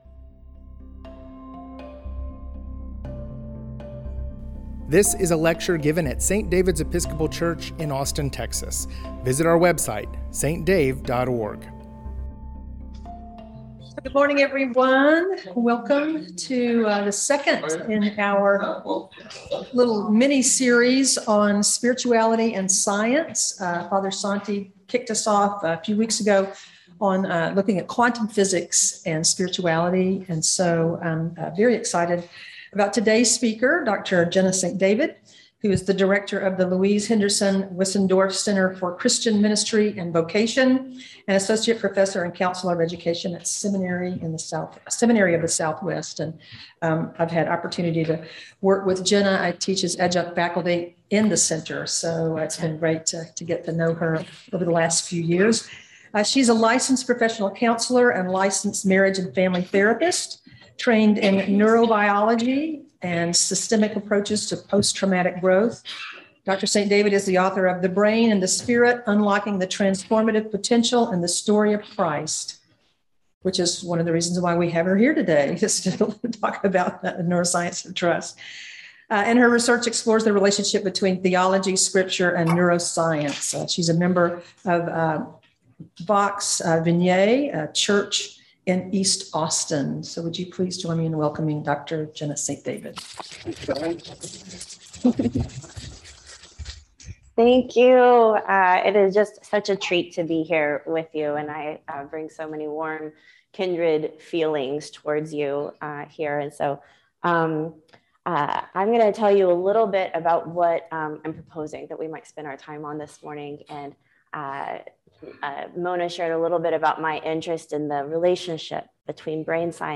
Spirituality and Science Lecture Series: The Neuroscience of Trust